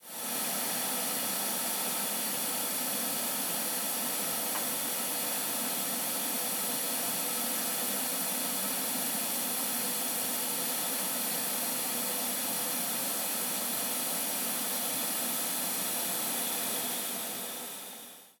Cisterna de wáter 2
cisterna
Sonidos: Agua
Sonidos: Hogar